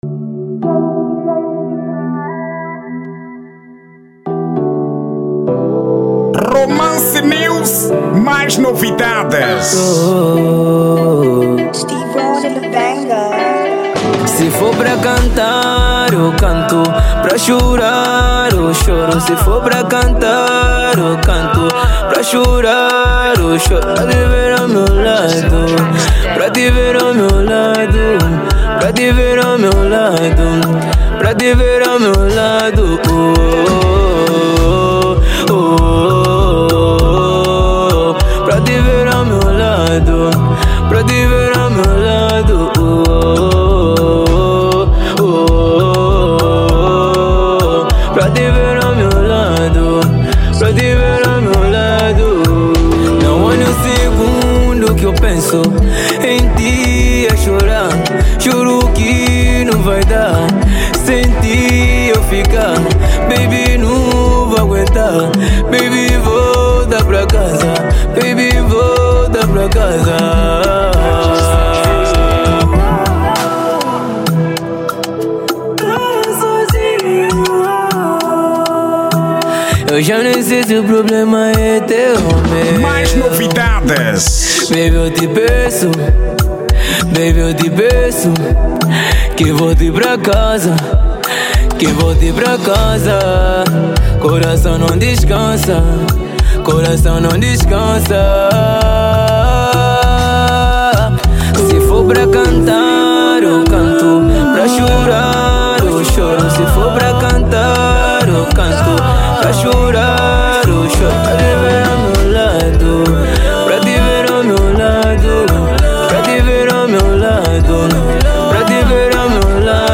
Estilo: Rap Trap Vibe